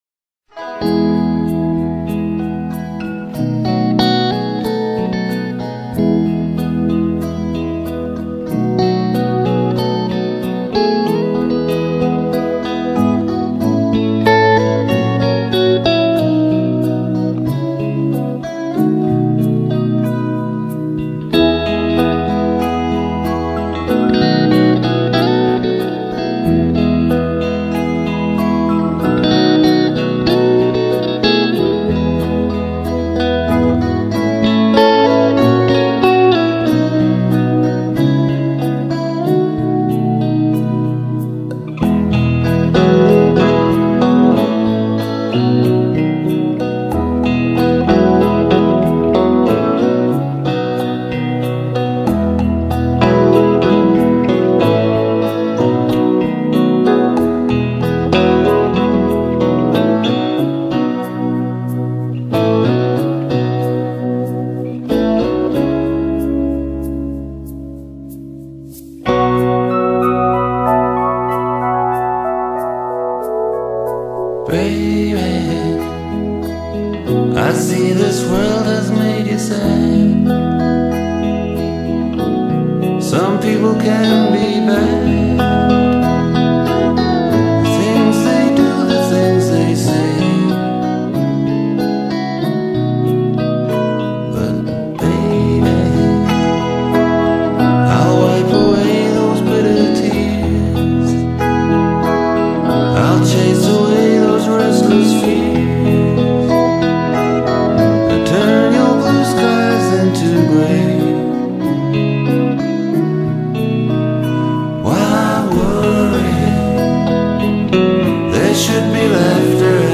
펑크음악과 거리가 먼 펍 록(pub rock)에 기초를 두고 심지어
록에 기반을 둔 사운드를 구사했고, 때때로 재즈와 컨트리 음악,